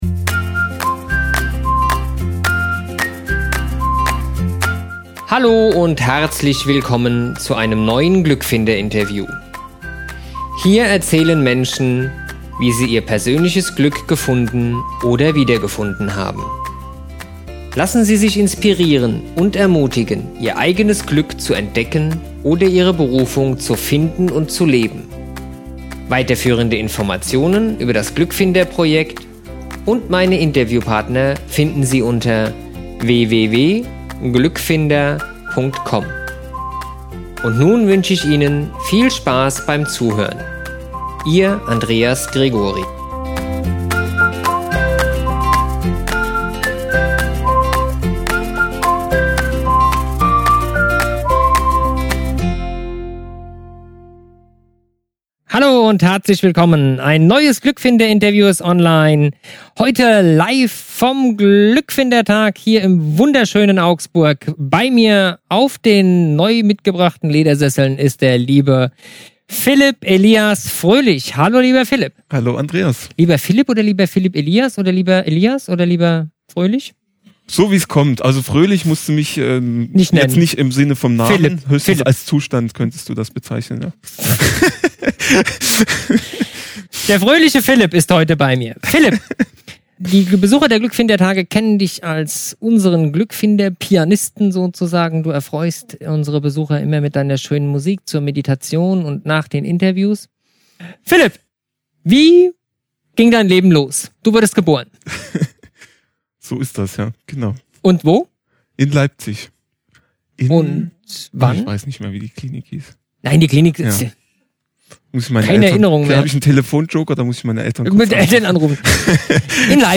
Am Glückfinder Tag in Augsburg